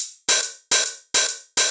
VTDS2 Song Kit 07 Pitched If You Know Hihat Mix.wav